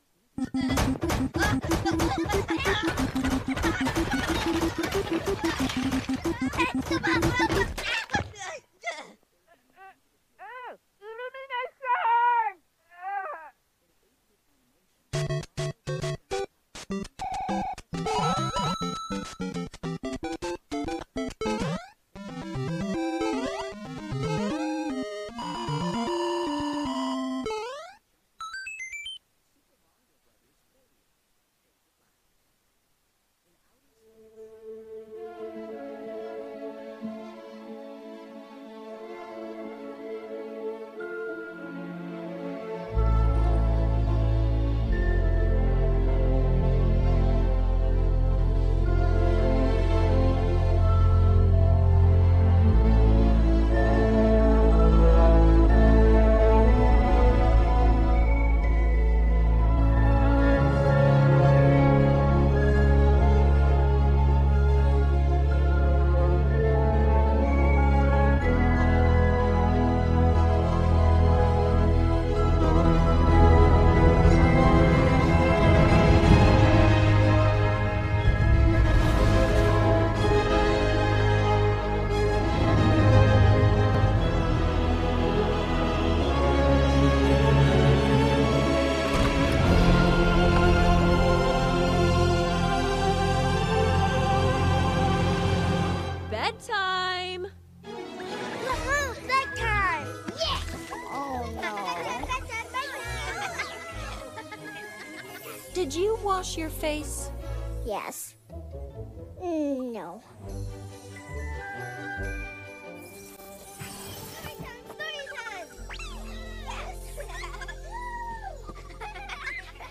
The mario galaxy movie synced with english cam audio Here is the audio, can someone sync it with this?